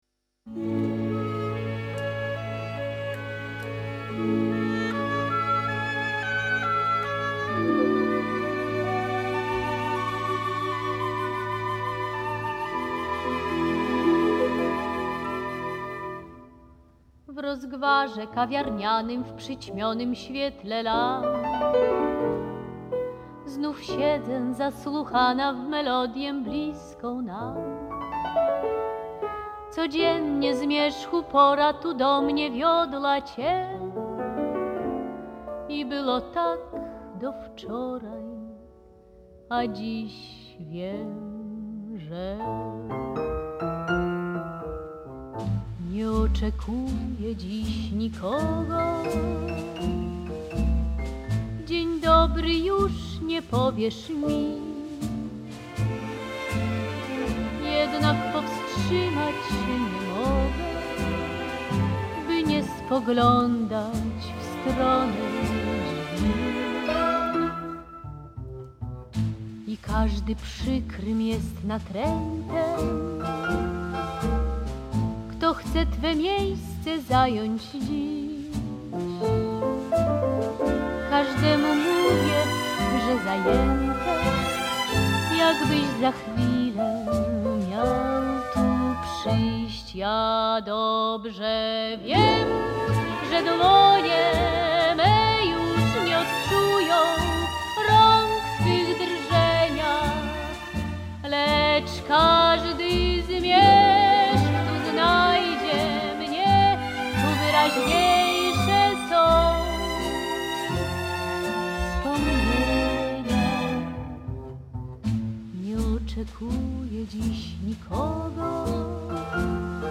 slow-fox
(period recording)